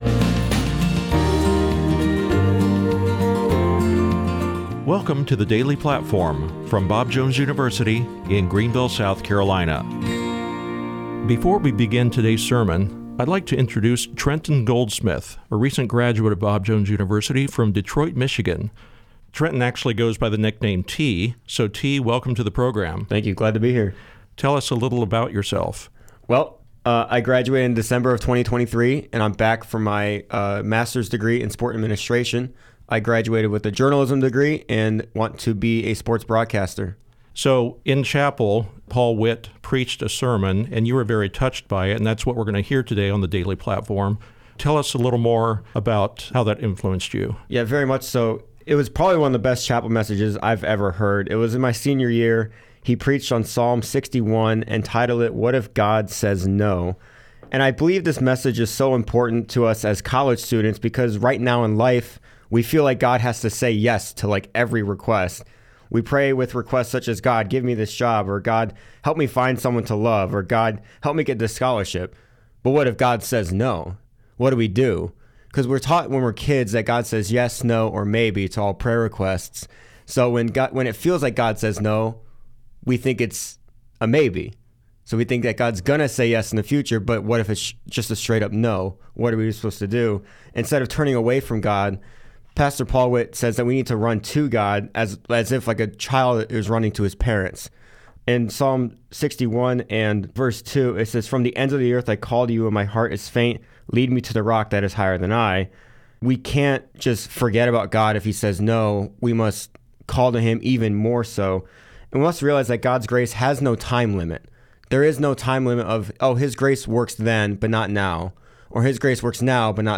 ” with a message from 1 Thessalonians 1:9.